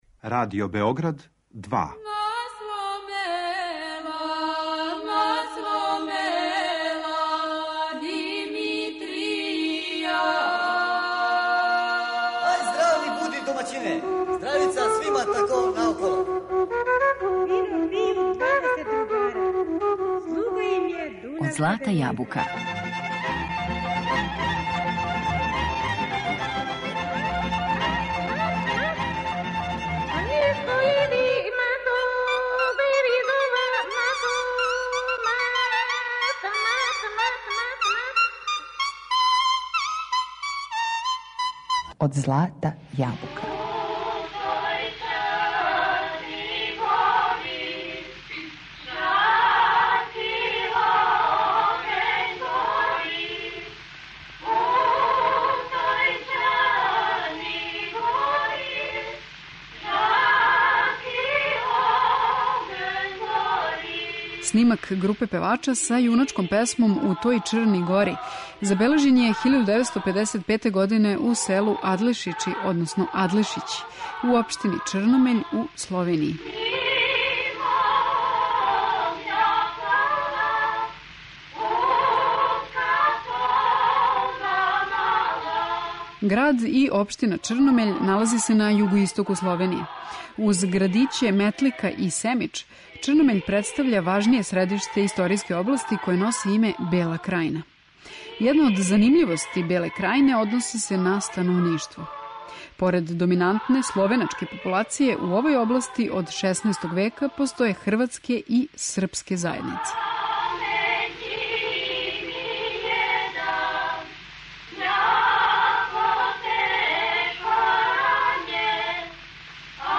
Бела Крајина - ретки теренски записи